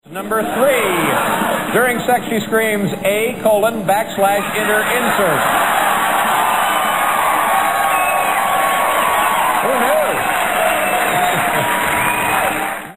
3) She screams
Category: Television   Right: Personal